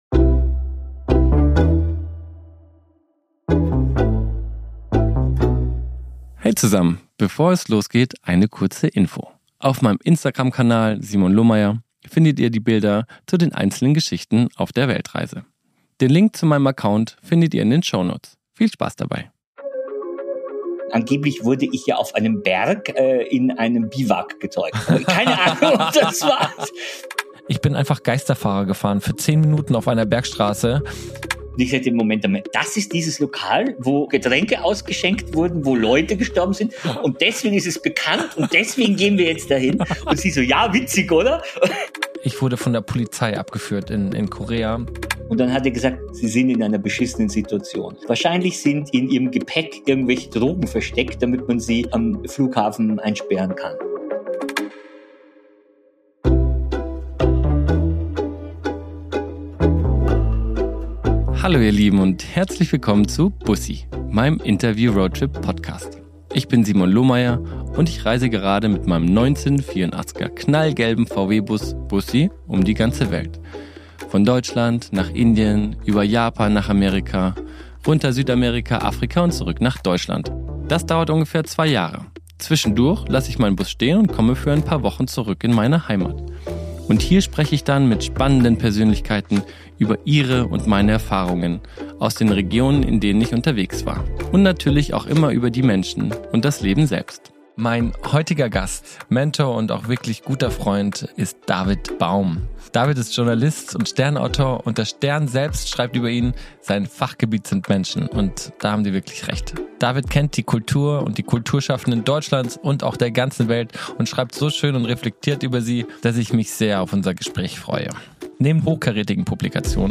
~ BUSSI - Ein Interview-Roadtrip Podcast